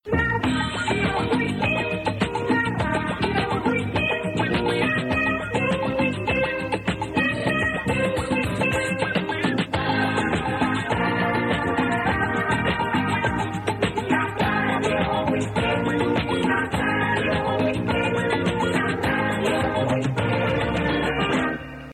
Jingle del programa